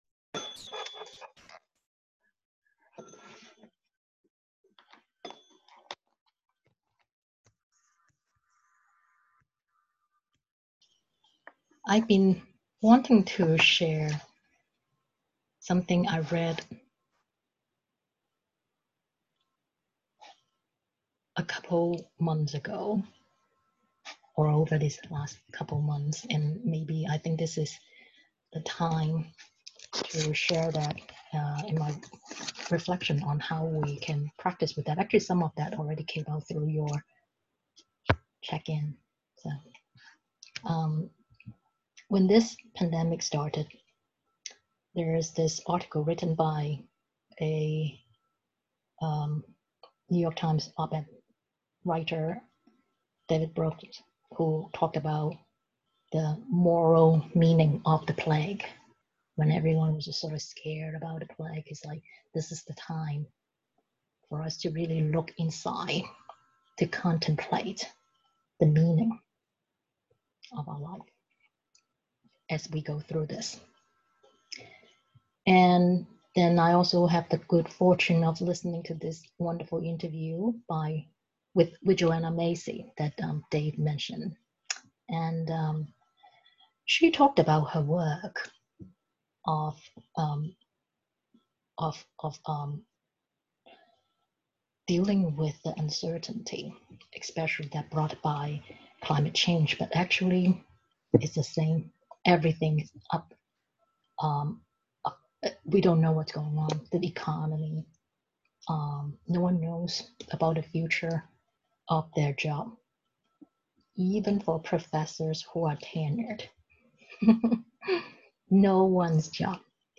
This talk was given in the Online Weekly Dharma Practice Gathering on May 22, 2020.